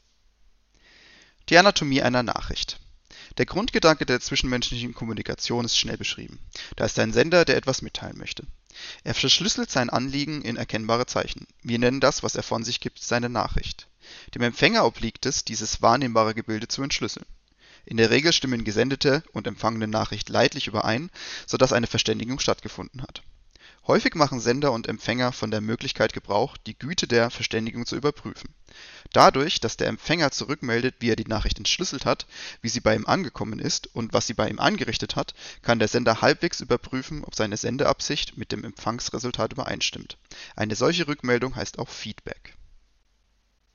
Um einen Eindruck von der Mikrofonqualität zu bekommen, wurde ein kurzer Ausschnitt aus dem Buch "Miteinander reden: 1 - Störungen und Klärungen, Allgemeine Psychologie der Kommunikation" von Friedemann Schulz von Thun (erschienen im Rowohlt Taschenbuch Verlag) vorgelesen und aufgenommen.
Steelseries Arctis 7 Originalaufnahme
Klanglich, sowohl auf empfangender als auch auf sendender Seite, weiss das Headset zu überzeugen und der Tragekomfort ist auch zu loben.